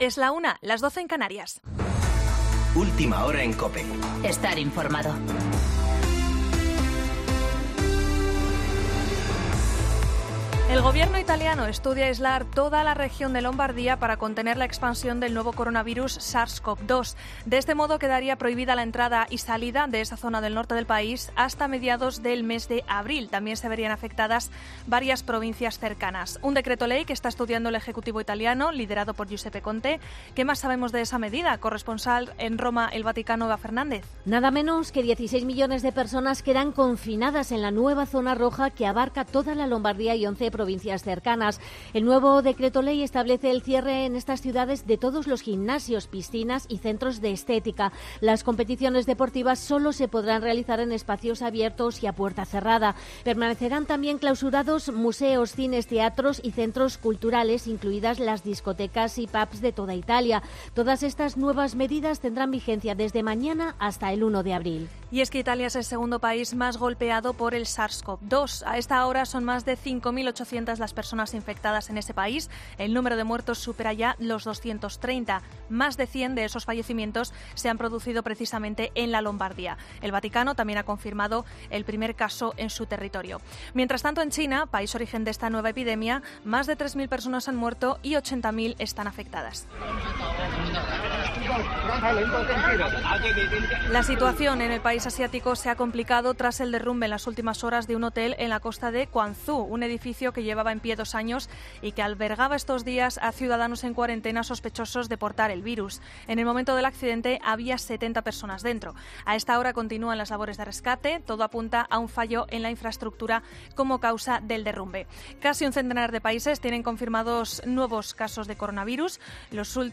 Boletín de noticias COPE del 8 de marzo de 2020 a las 1.00 horas